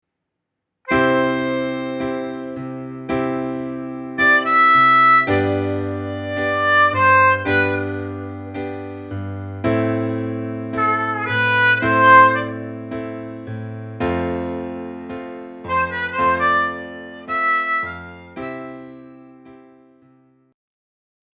• начать использовать паузы
S-pauzami.mp3